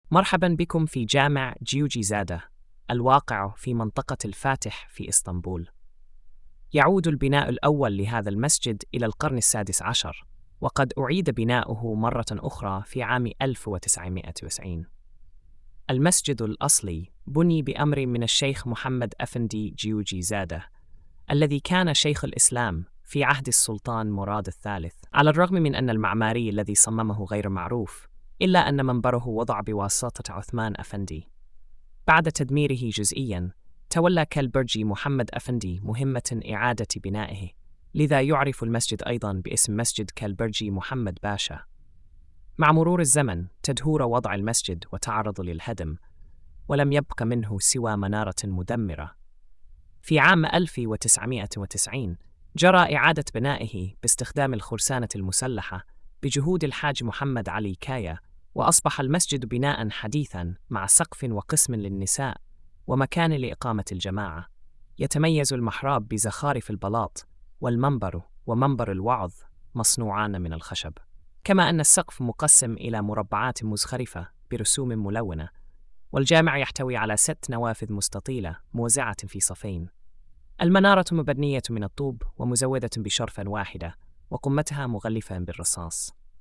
السرد الصوتي